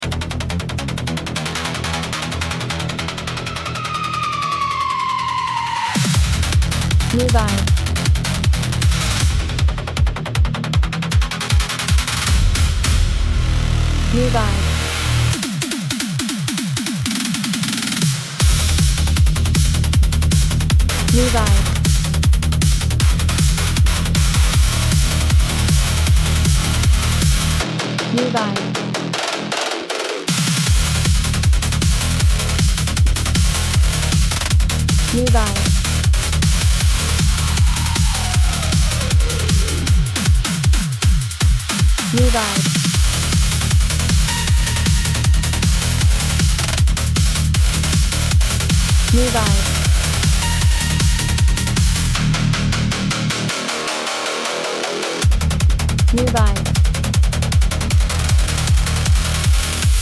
Genre: Dynamic, hard beat